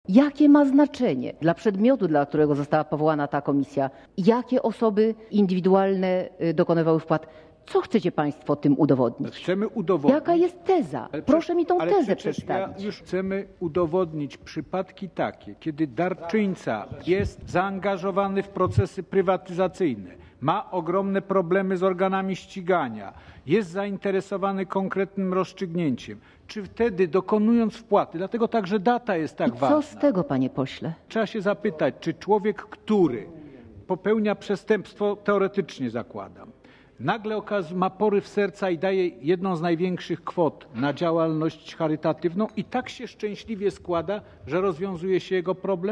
kwasniewska_-_klotnia.mp3